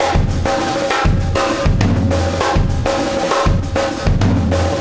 Extra Terrestrial Beat 20.wav